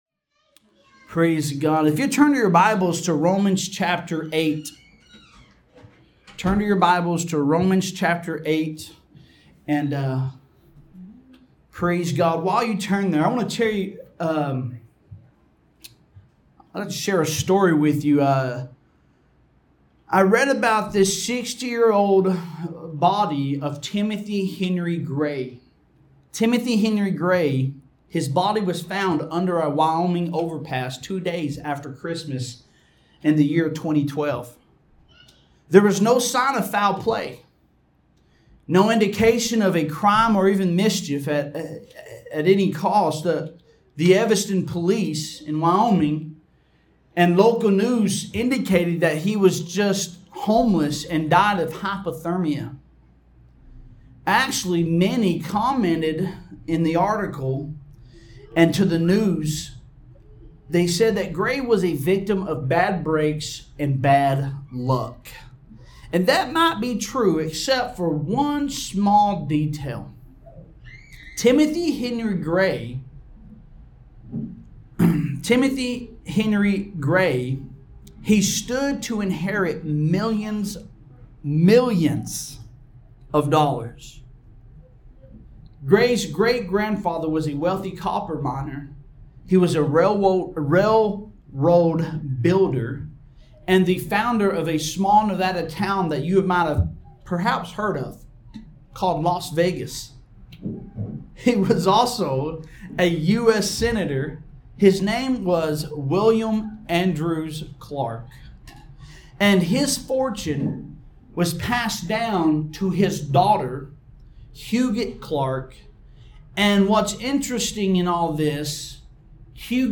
Sermons | Knoxville Potters House Church